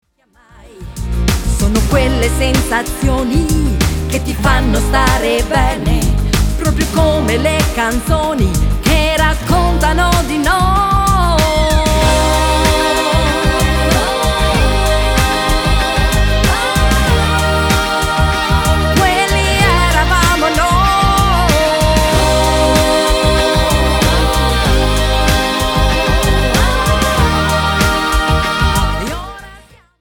MODERATO  (3.42)